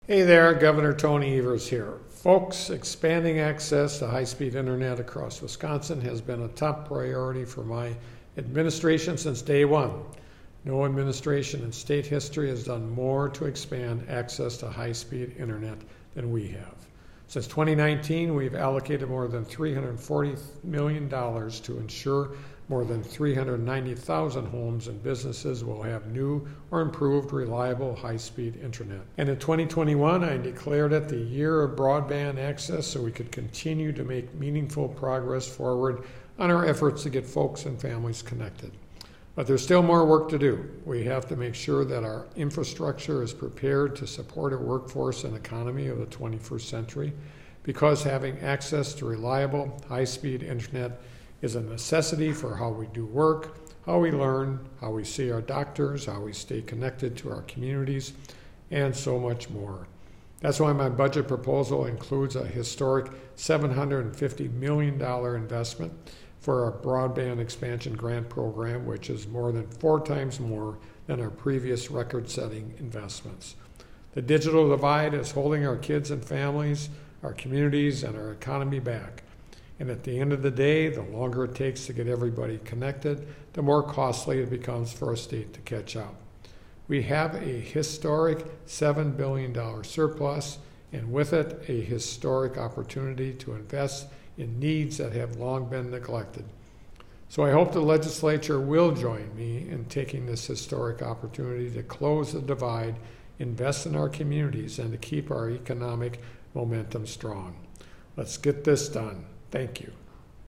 Weekly Dem radio address: Gov.
MADISON — Gov. Tony Evers today delivered the Democratic Radio Address highlighting his plan to expand high-speed internet across Wisconsin, including a historic investment of $750 million for the Broadband Expansion Grant Program. This historic proposal builds upon the governor’s previous record-setting investments to expand broadband across the state and is more than four times larger than the total funding provided to the grant program over Gov. Evers’ past two budgets.